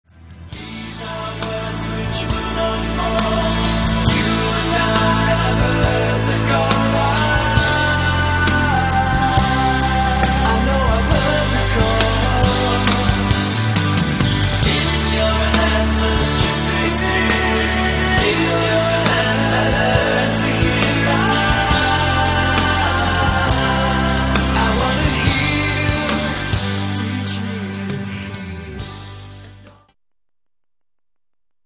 features four of the band members